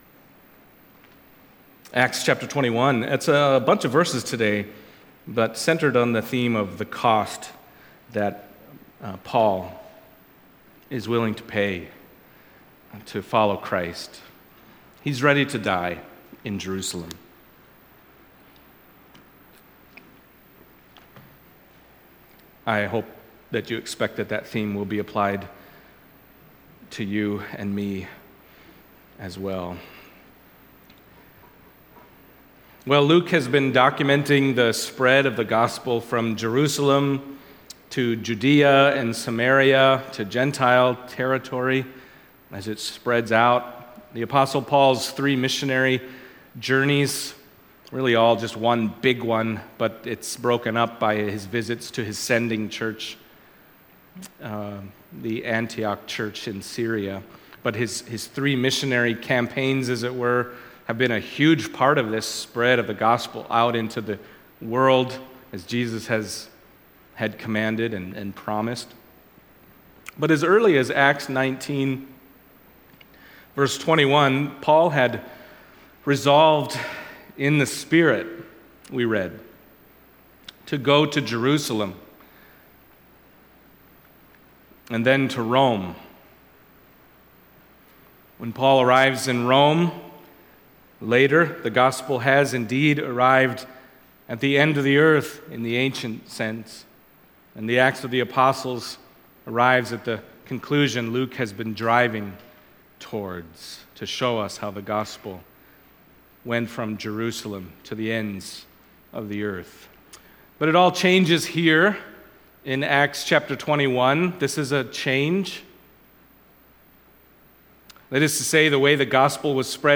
Acts Passage: Acts 21:1-31 Service Type: Sunday Morning Acts 21:1-31 « Paul’s Emotional Farewell